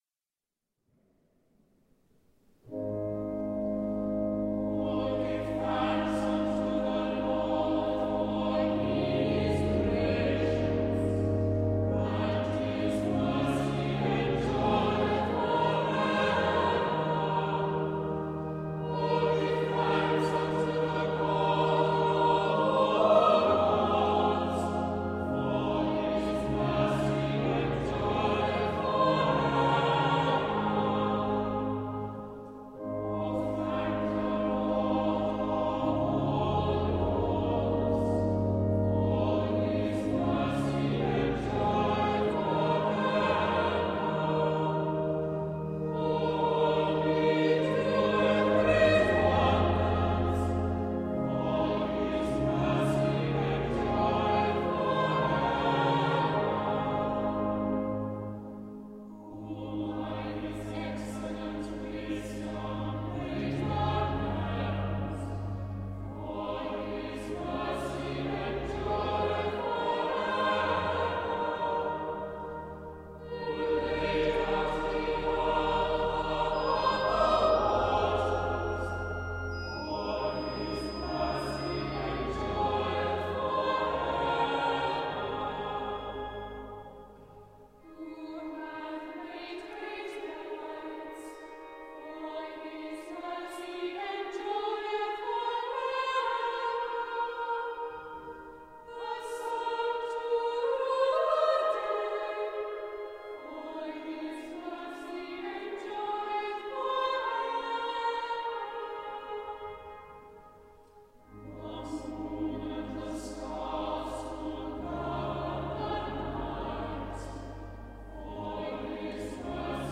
For those recordings made during live services, the permission of the appropriate authority was sought and obtained.